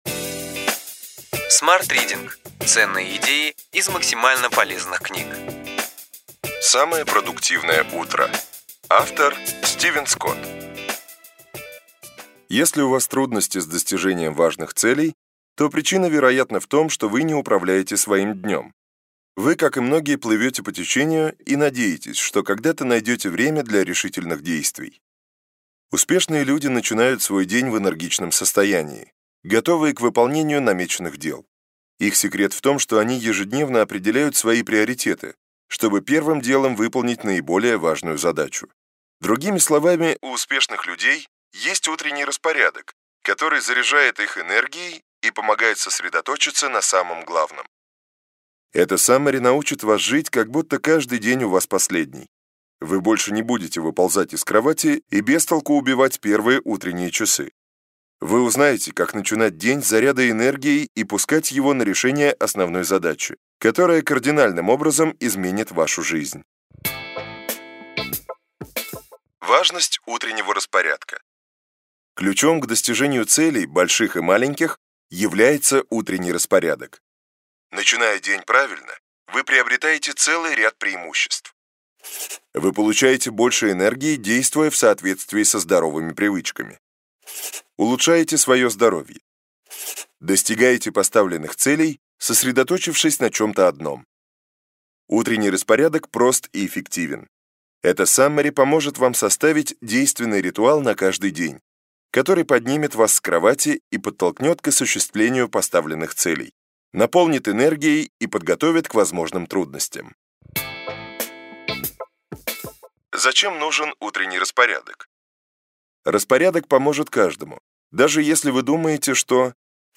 Аудиокнига Ключевые идеи книги: Самое продуктивное утро.